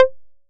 edm-perc-17.wav